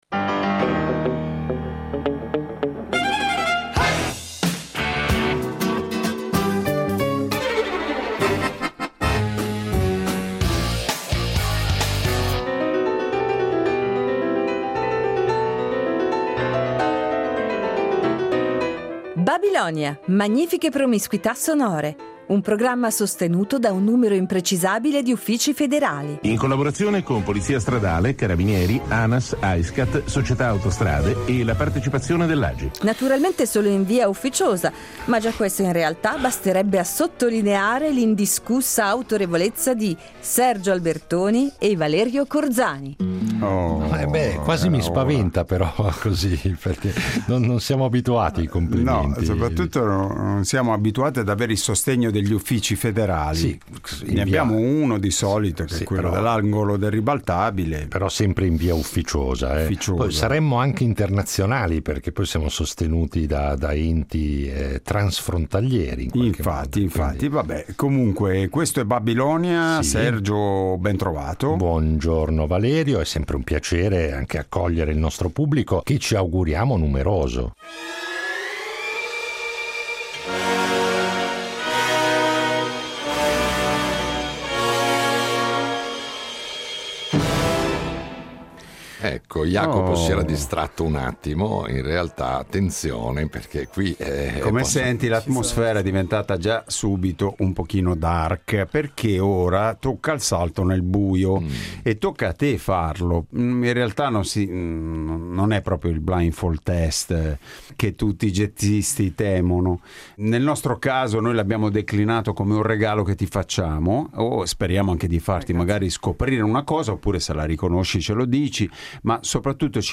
Ecco perché ci sembra doveroso recuperarlo, di tanto in tanto, riunendolo in puntate particolarissime perché svincolate dall’abituale scansione di rubriche e chiacchierate con gli ospiti. Itinerari ancor più sorprendenti, se possibile, che accumulano in modo sfrenato i balzi temporali e di genere che caratterizzano da sempre le scalette di Babilonia .